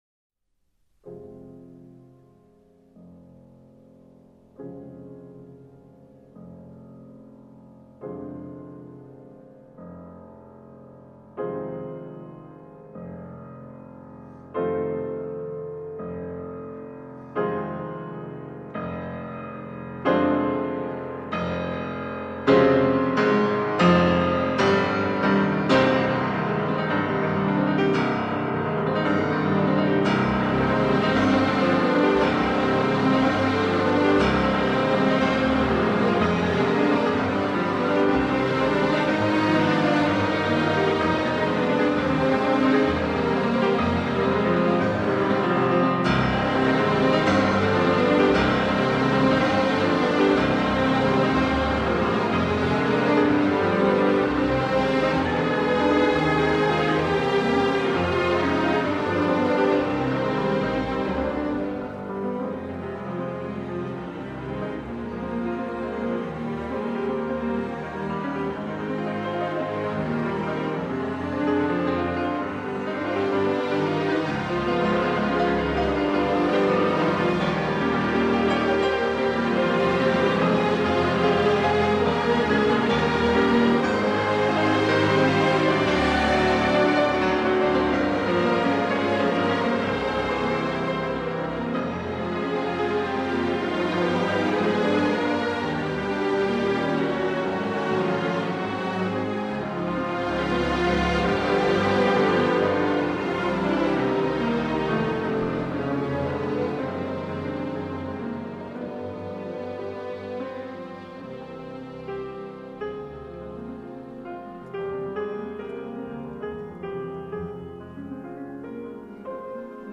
Rahmaninov_Rihter_Koncert__2_cmoll_op_18_1ch__Moderato__.mp3